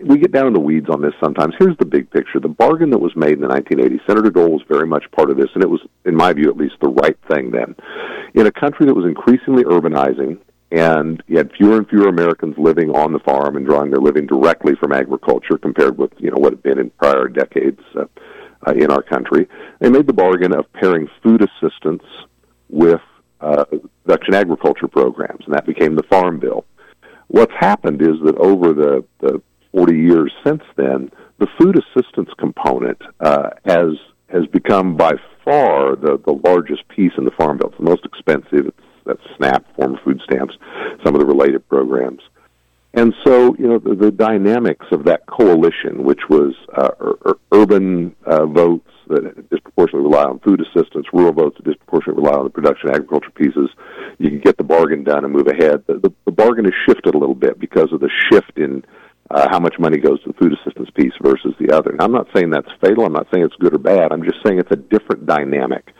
Kansas Congressman Derek Schmidt took some time to update KVOE listeners on agriculture policy and overall budgeting as part of his monthly visit on the KVOE Morning Show on Friday.